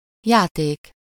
Ääntäminen
IPA: [ʒø]